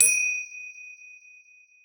TM88 BellHi-Hat.wav